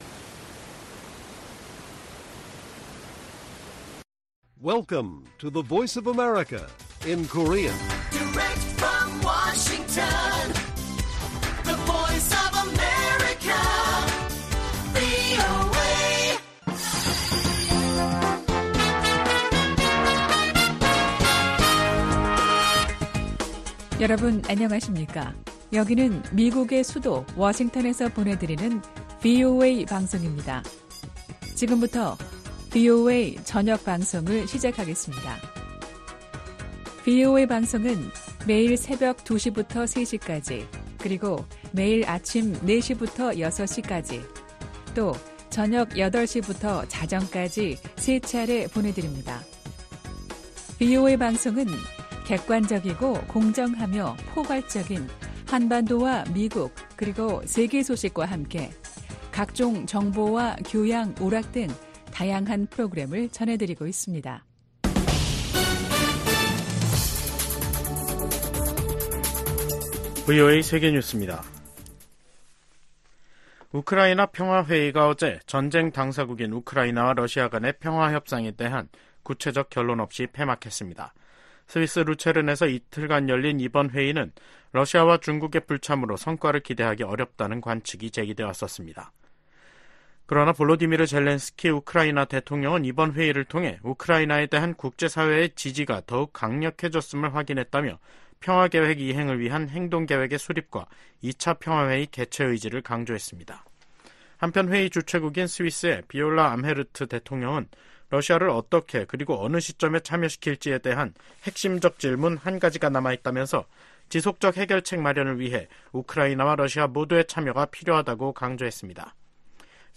VOA 한국어 간판 뉴스 프로그램 '뉴스 투데이', 2024년 6월 17일 1부 방송입니다. 미국,영국,프랑스 등 주요 7개국, G7 정상들이 북한과 러시아간 군사협력 증가를 규탄했습니다. 미국 하원이 주한미군을 현 수준으로 유지해야 한다는 내용도 들어있는 새 회계연도 국방수권법안을 처리했습니다. 북한의 대러시아 무기 지원으로 우크라이나 국민의 고통이 장기화하고 있다고 유엔 주재 미국 차석대사가 비판했습니다.